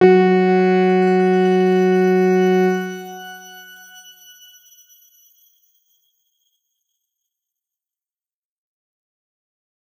X_Grain-F#3-mf.wav